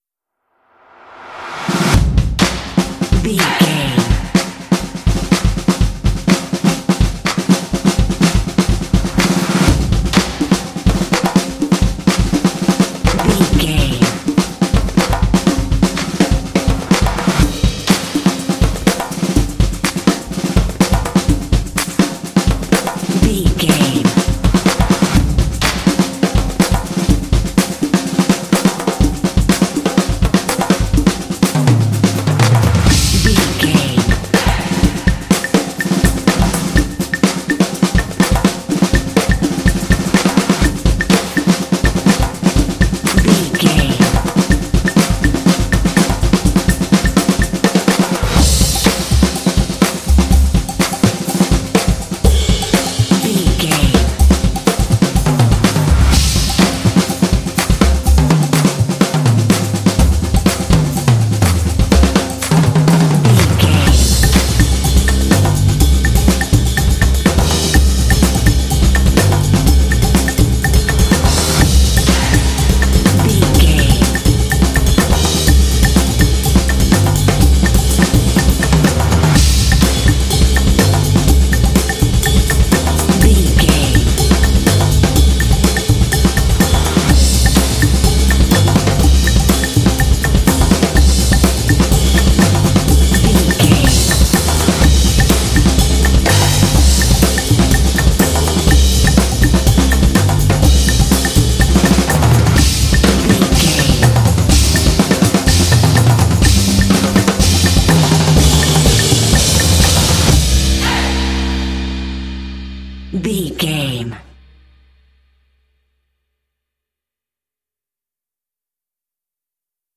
This epic drumline will pump you up for some intense action.
Epic / Action
Atonal
driving
determined
drums
percussion
vocals
bass guitar
drumline